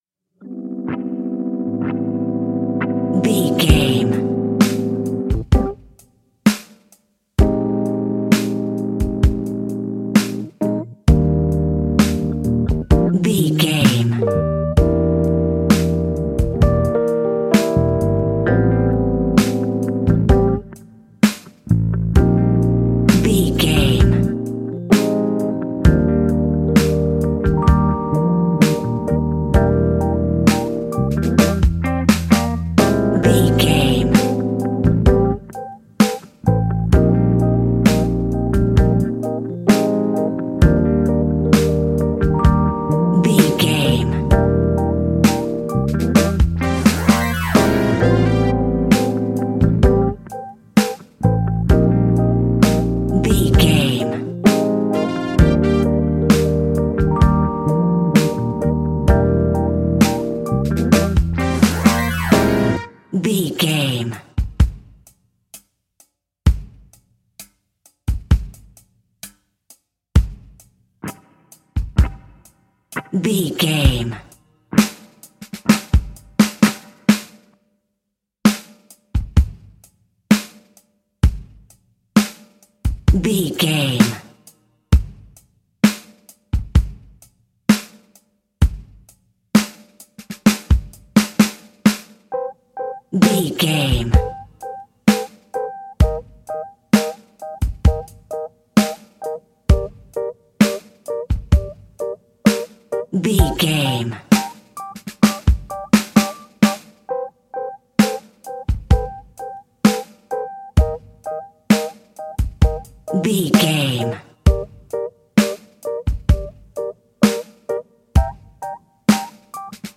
Ionian/Major
hip hop
instrumentals